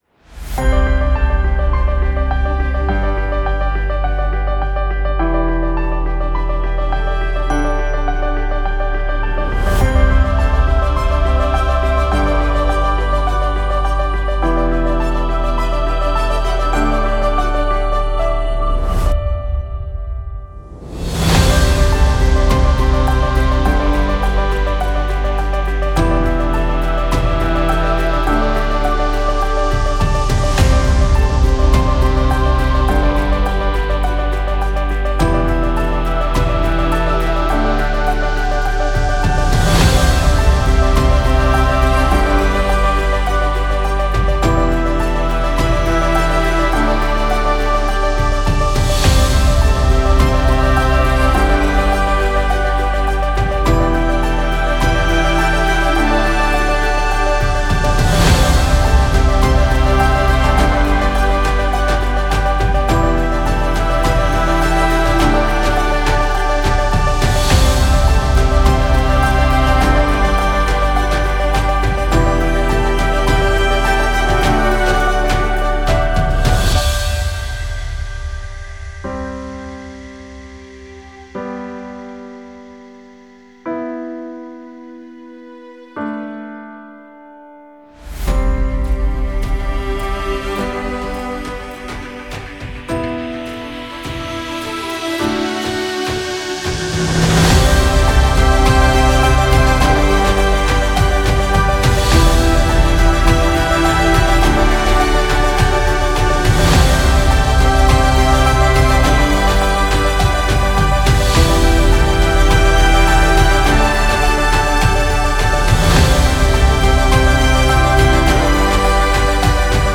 Genre: filmscore, corporate.